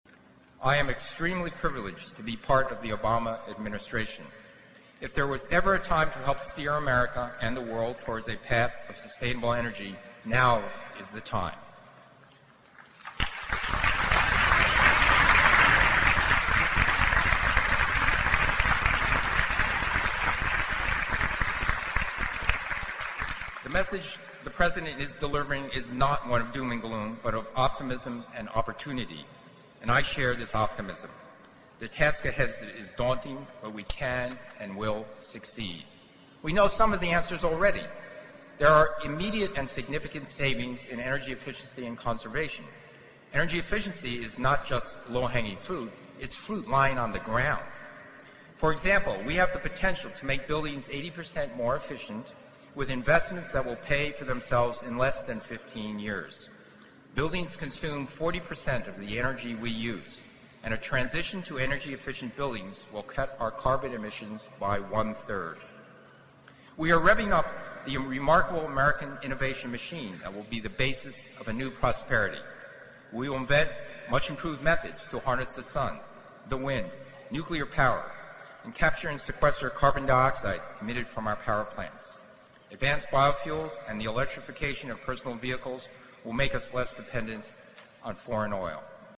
借音频听演讲，感受现场的气氛，聆听名人之声，感悟世界级人物送给大学毕业生的成功忠告。同时，你可以借此机会跟世界顶级人物学习口语，听他们的声音，模仿地道的原汁原味的腔调。